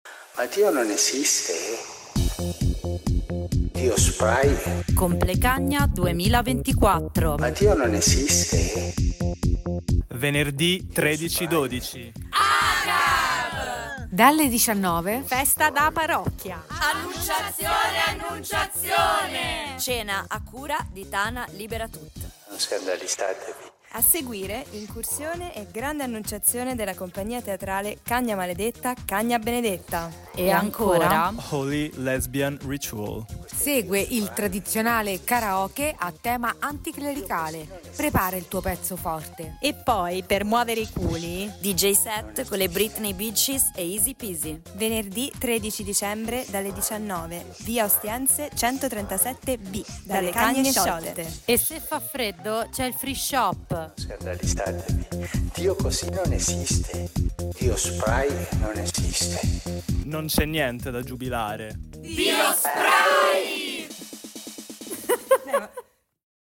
complecagne spot .ogg